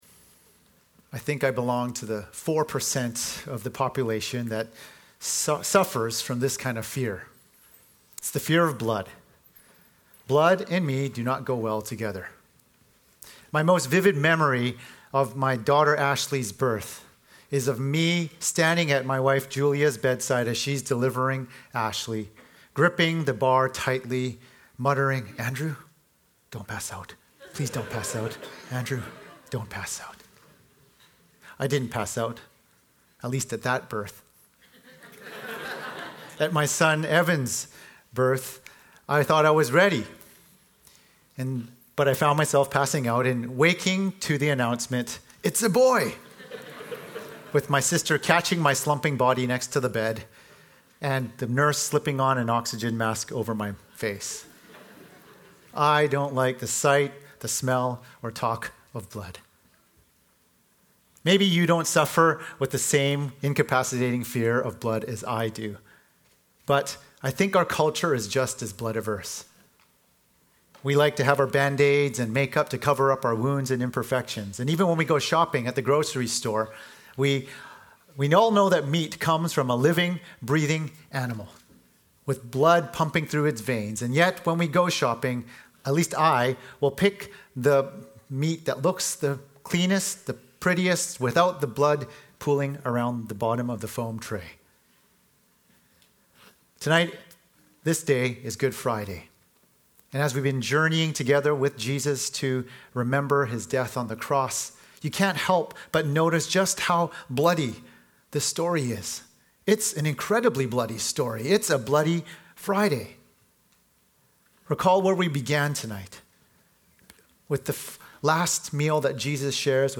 Good Friday 2015
Episode from Tenth Church Sermons